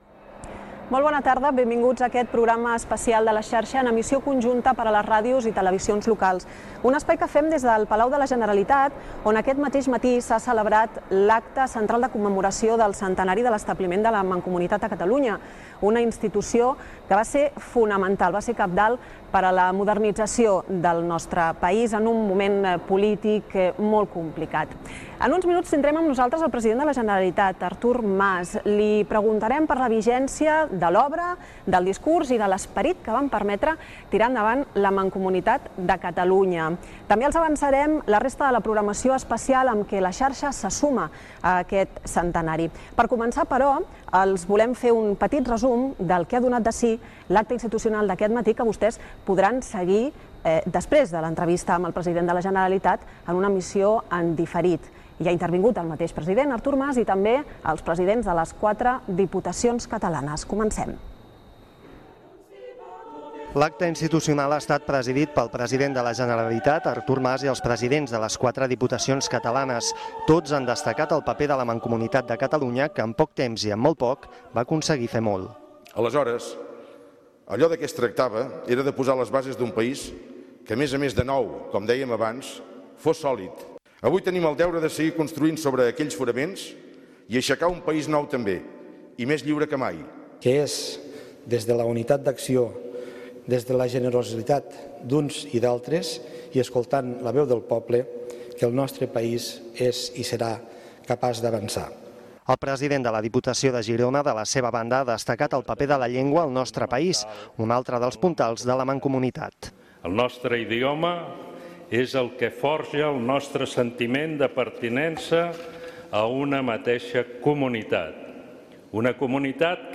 Fragment de la transmissió des del Palau de la Generalitat, a Barcelona. Presentació, sumari del programa, resum de l'acte institucional fet aquell matí i entrevista amb el President de la Generalitat Artur Mas.
Informatiu